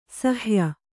♪ shya